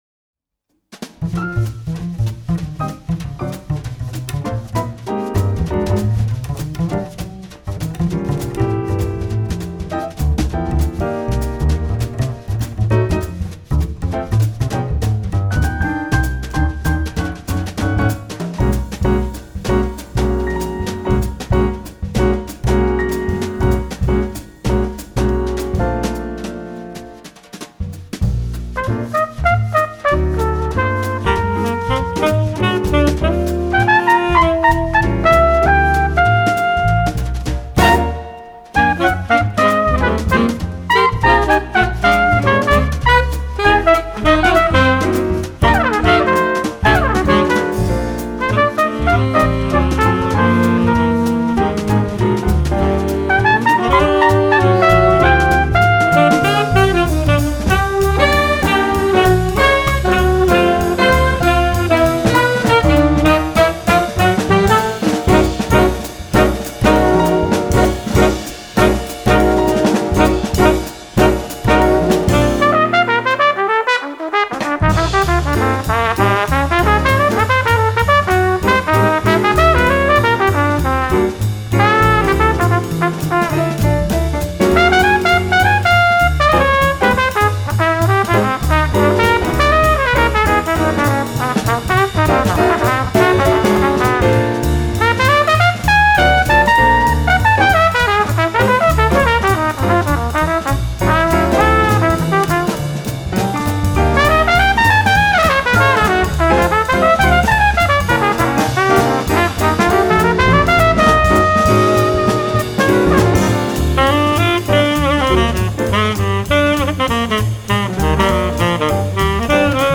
double bass
tenor sax
alto sax
trumpet
piano
drums